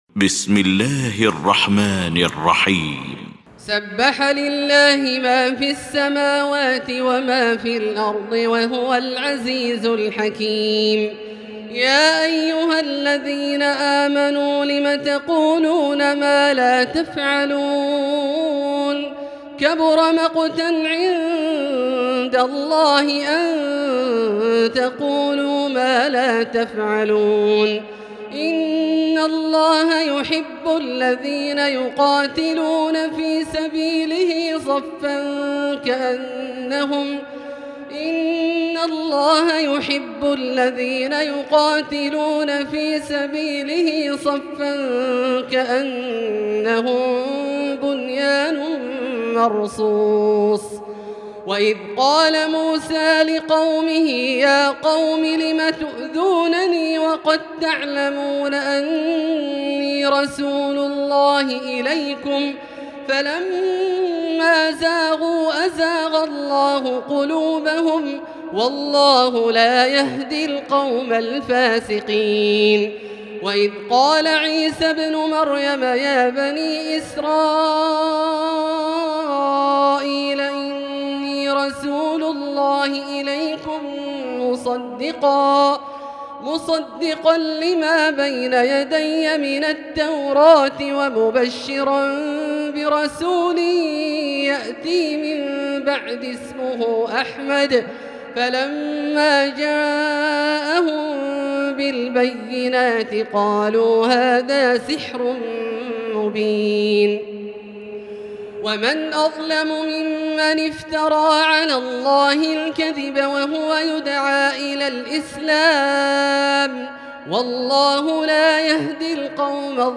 المكان: المسجد الحرام الشيخ: فضيلة الشيخ عبدالله الجهني فضيلة الشيخ عبدالله الجهني الصف